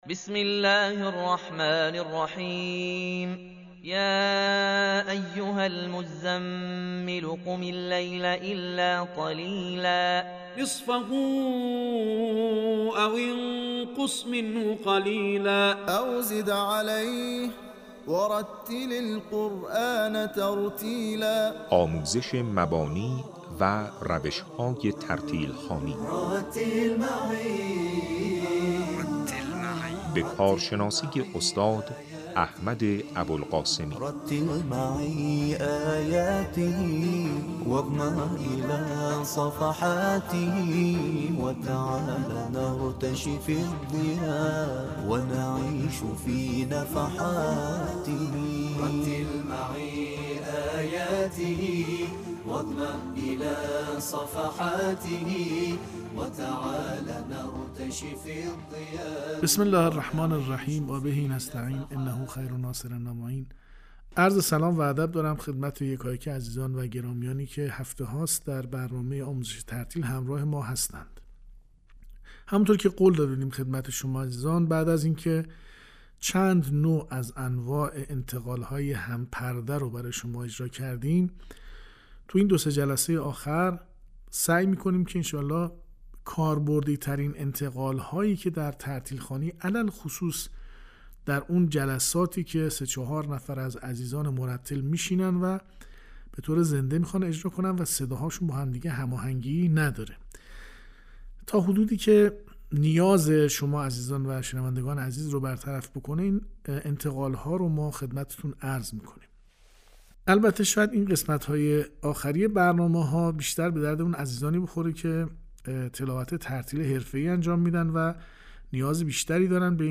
دوازدهمین جلسه آموزش ترتیل